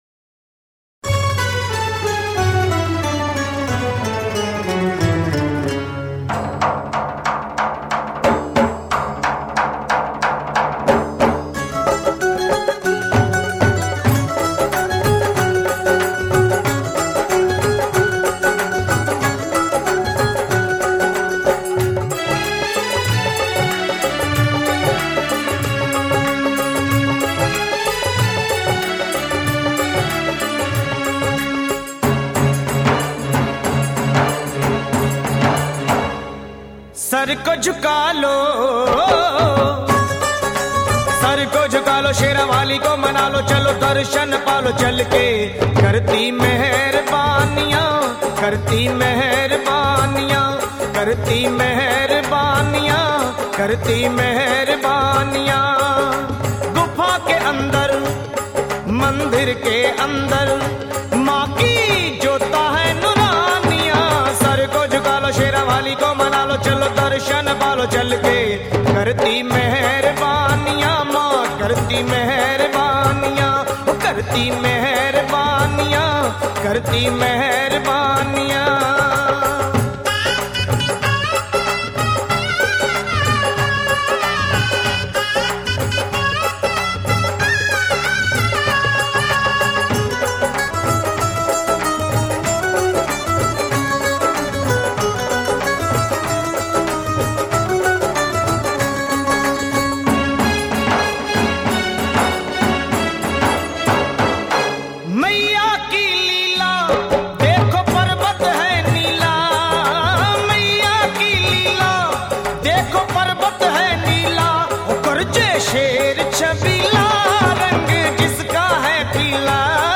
Best Devotional bhajan Song Top Navratri Bhajans